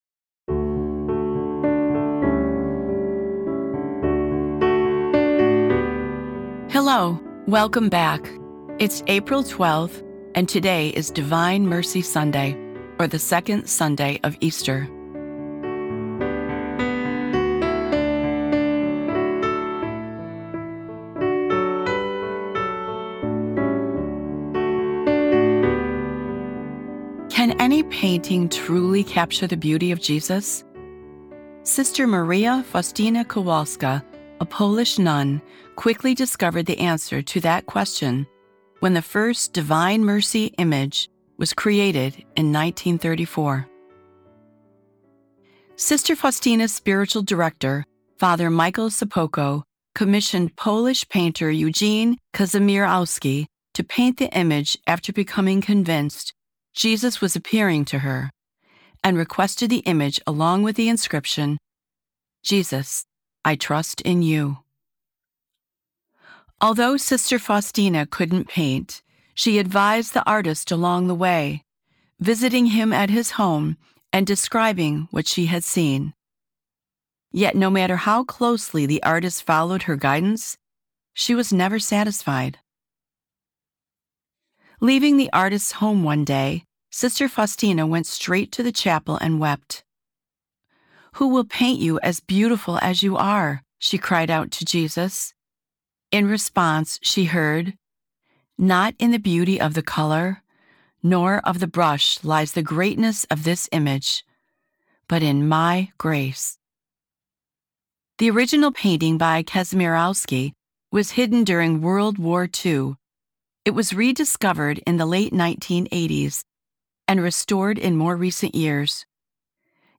Today's episode of Sundays with Bishop Ken is a reading from The Little White Book: Easter 2026.